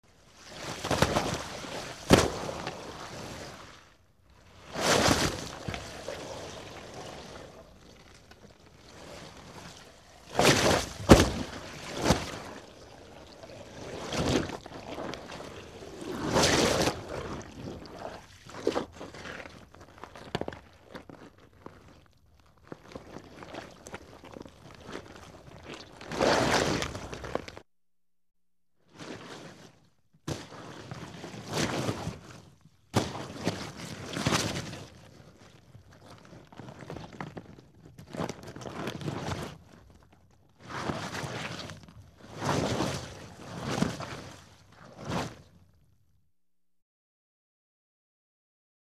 Alien Walking; Large Creature Slithers Through Swamp Or Sewer.